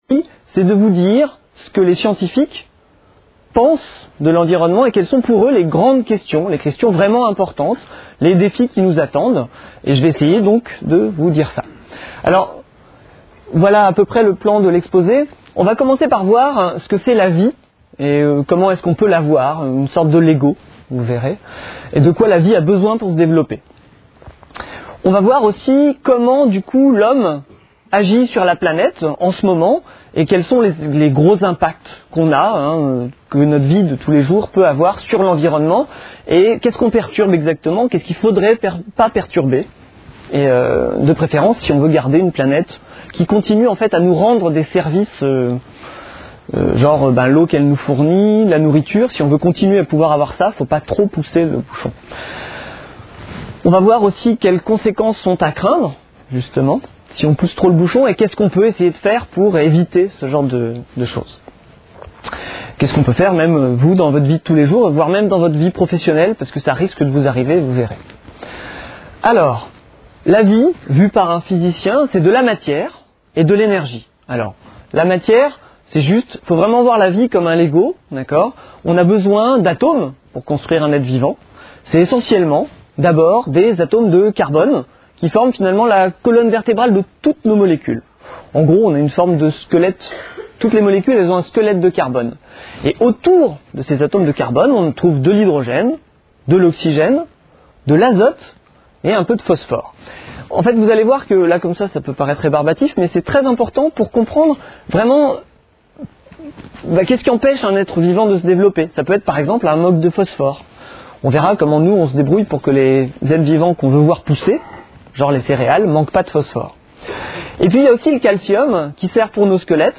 Une conférence de l'UTLS au Lycée